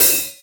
• Crash Cymbal One Shot E Key 07.wav
Royality free crash cymbal one shot tuned to the E note. Loudest frequency: 9989Hz
crash-cymbal-one-shot-e-key-07-4ci.wav